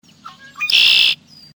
Yesterday I heard one of those familiar signs during my walk around Red House Lake!!
Red-winged Blackbirds are finally back and it shouldn’t be too long before other birds start returning.